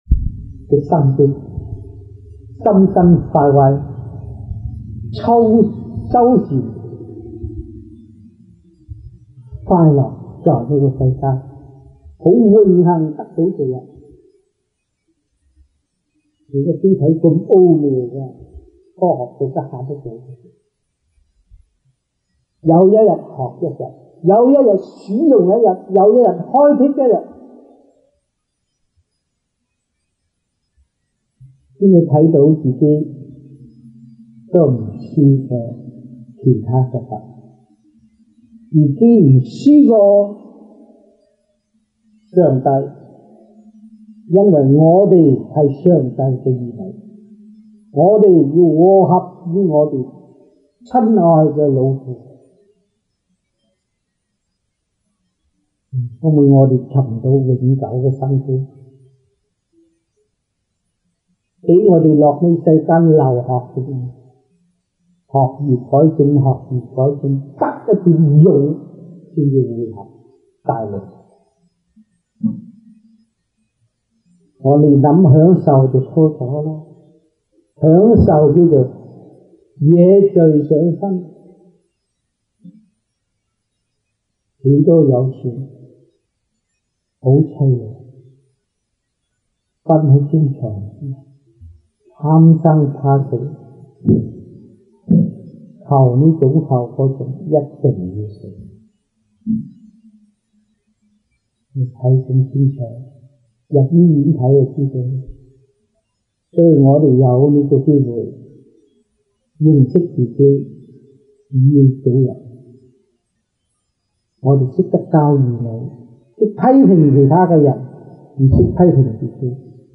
Lectures-Chinese-1982 (中文講座)